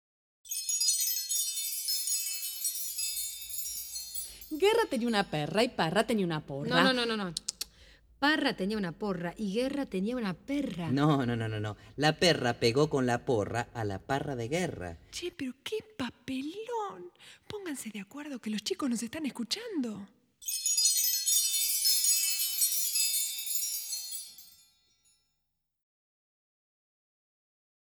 Música tradicional
Música vocal